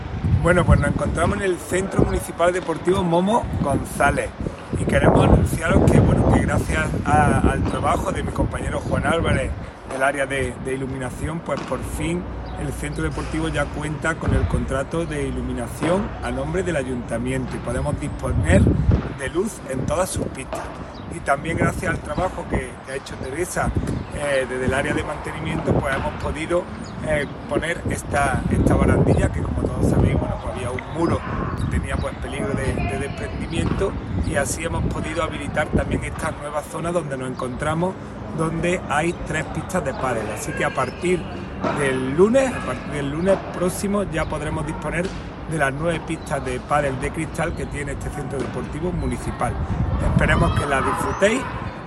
El teniente de alcalde delegado de Deportes, Juan Rosas, y la teniente de alcalde de Obras y Mantenimiento, Teresa Molina, informan de las últimas actuaciones de mejora que se han llevado a cabo en el Centro Deportivo Momo González de La Quinta y que permitirá, desde el próximo lunes 13 de febrero, el alquiler de tres nuevas pistas que se unen a las seis anteriormente disponibles, por lo que ya quedan a disposición de los usuarios un total de 9.
Cortes de voz